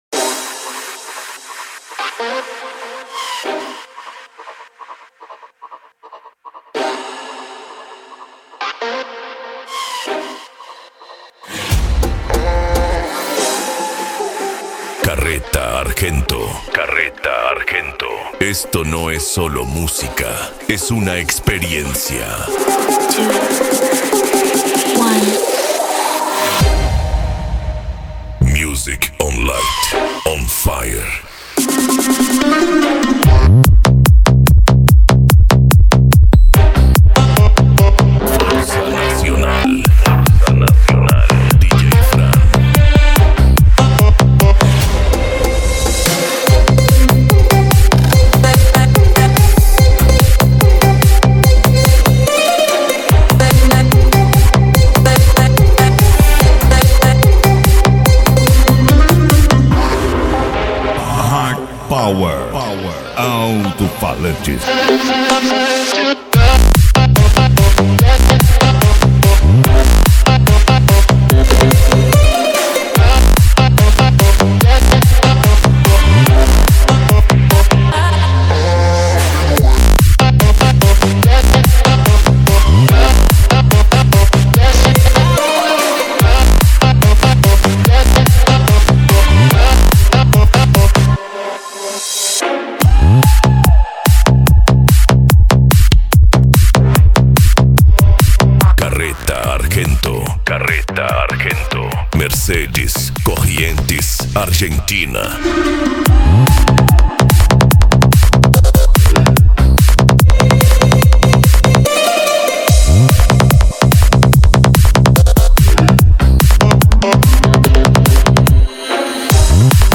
Bass
Psy Trance
Racha De Som
Remix